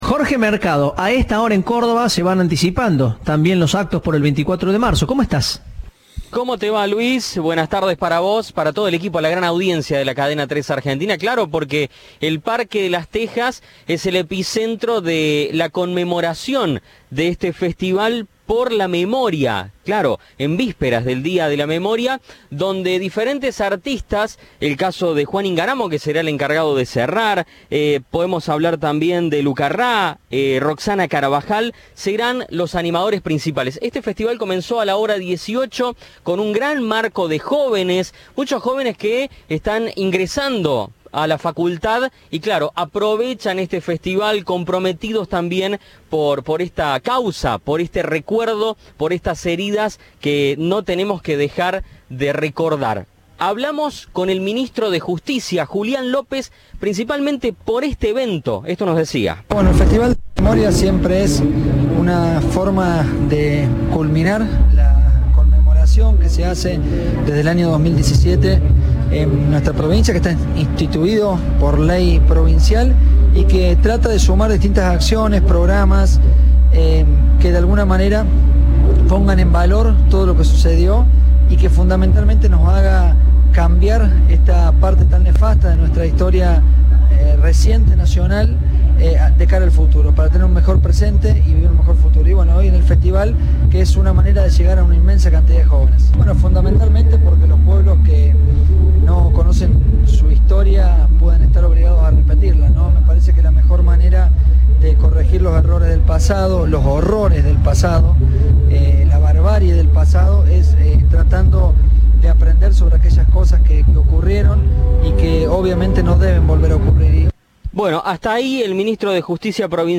En diálogo con Cadena 3, el ministro de Justicia y Derechos Humanos de Córdoba, Julián López, destacó la importancia del evento y de rememorar la fecha.